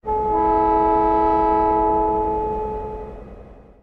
honk_long.ogg